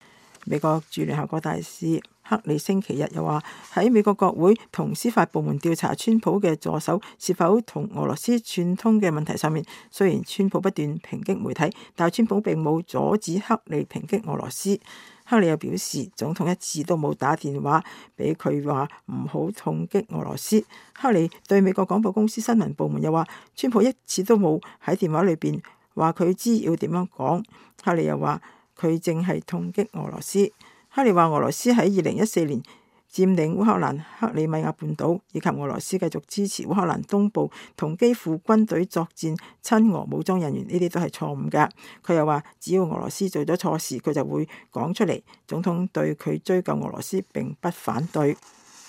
美國駐聯合國大使妮基黑利3月27日在聯合國講話。